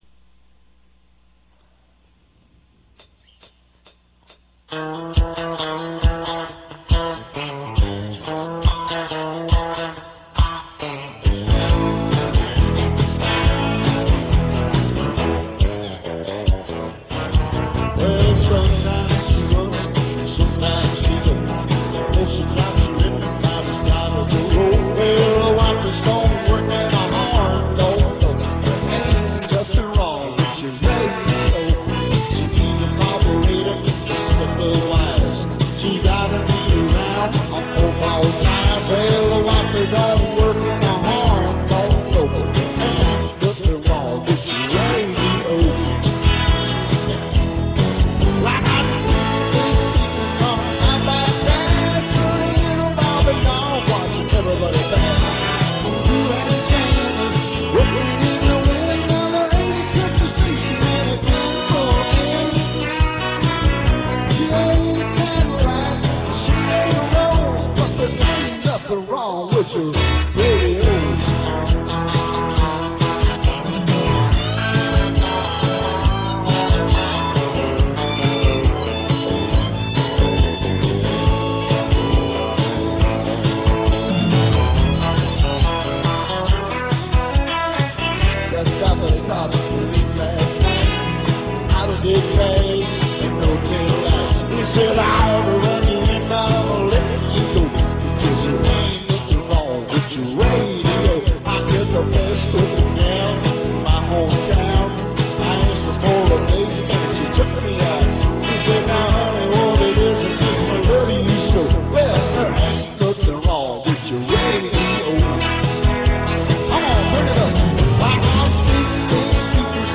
* * *Country Rock* * * live guitar and vocals